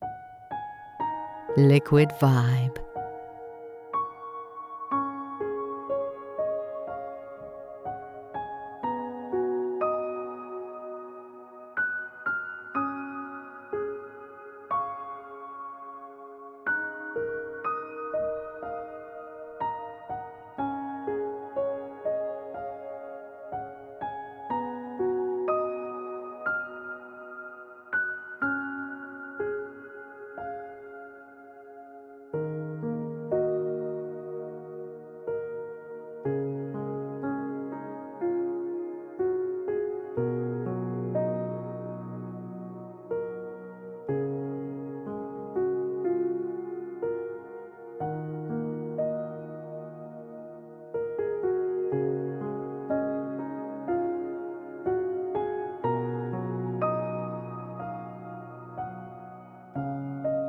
Enjoy a 1-Minute Sample – Purchase to Hear the Whole Track